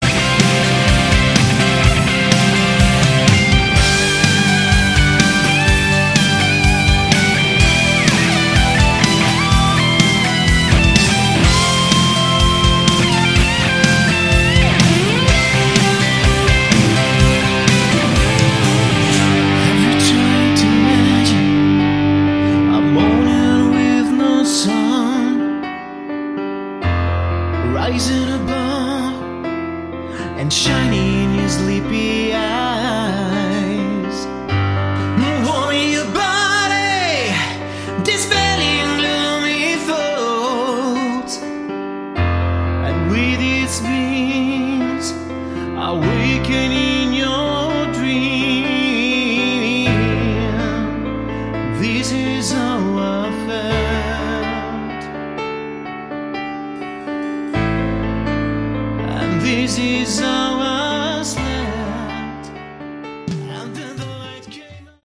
Category: AOR / Melodic Rock
lead, backing vocals
guitar, backing vocals
bass, backing vocals
keyboards, backing vocals
drums, percussion